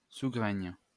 Sougraigne (French pronunciation: [suɡʁɛɲ]